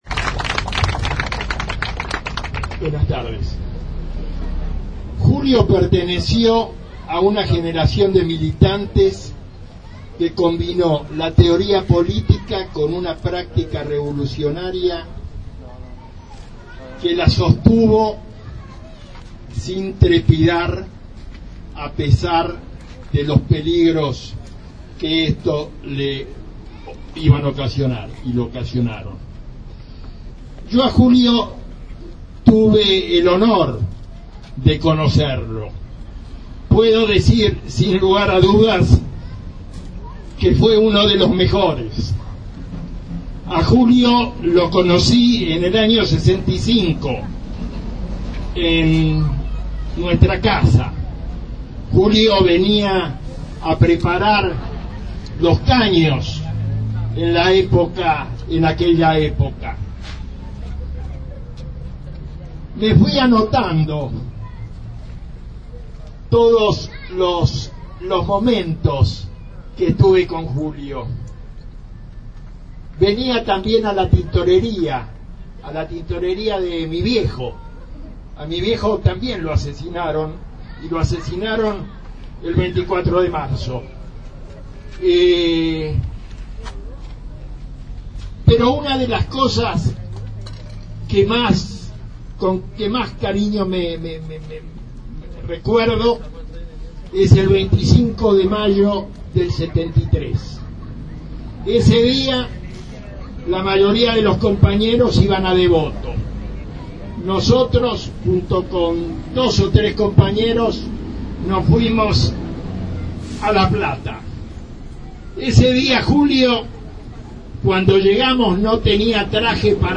En la esquina del pasaje Coronel Rico y avenida Suárez se descubrió una placa en su memoria.
Aquí algunos de los discursos que se escucharon.